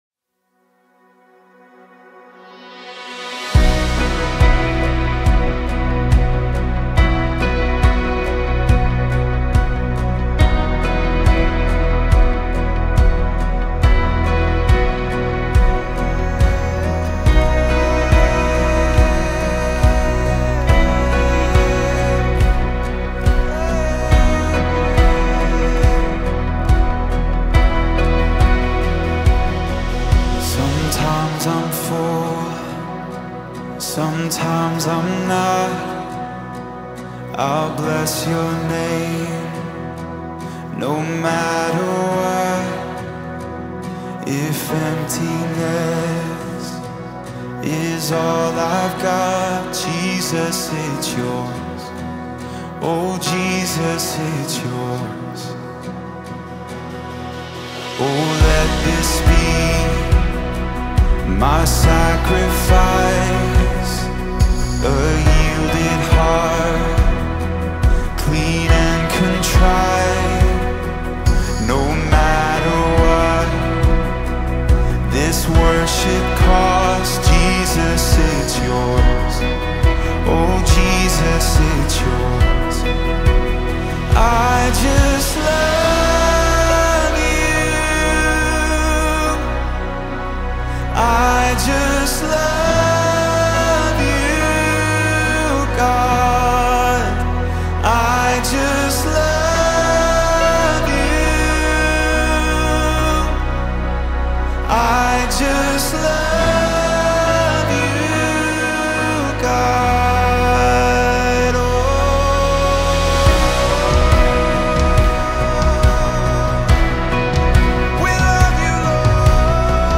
Original Key (B)